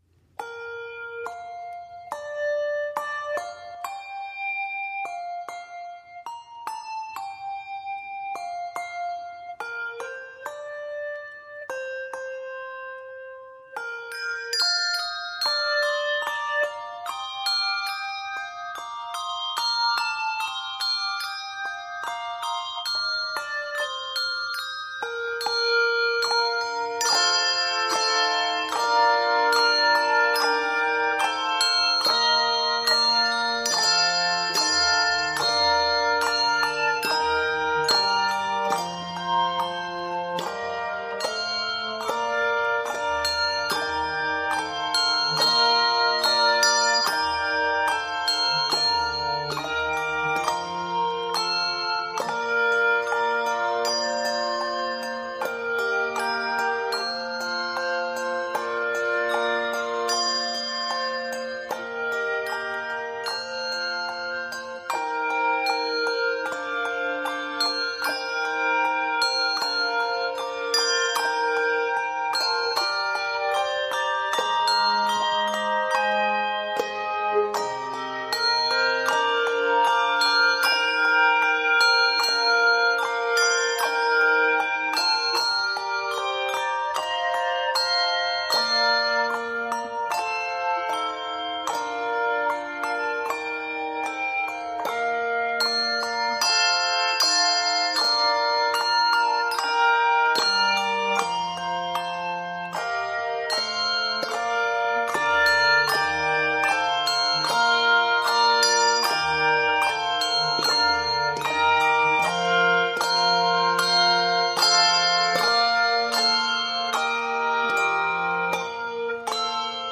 handbell choir
Octaves: 3-5